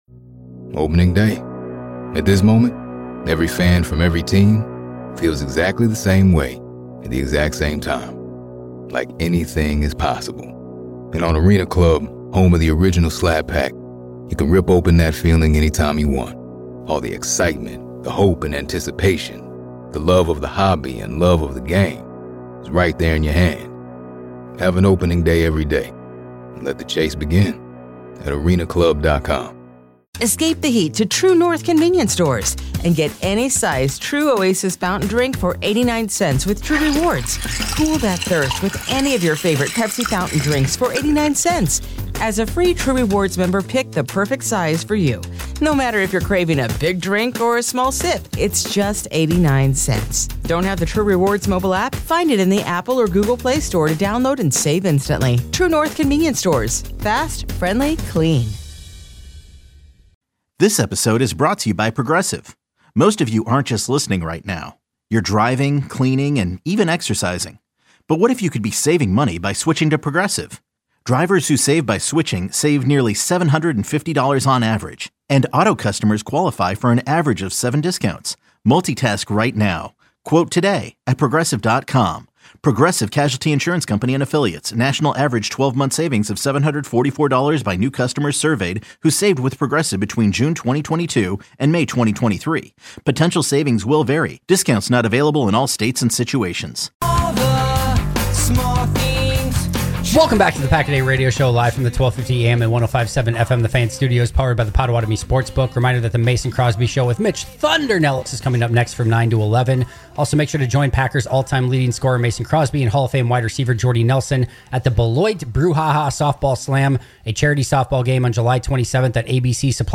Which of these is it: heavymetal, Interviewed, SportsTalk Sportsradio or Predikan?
SportsTalk Sportsradio